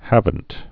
(hăvənt)